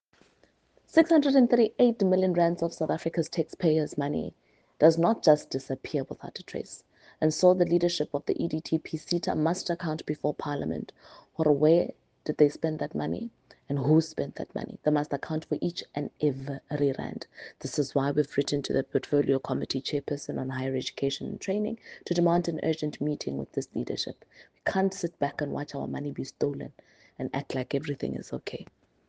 soundbite by Karabo Khakhau MP.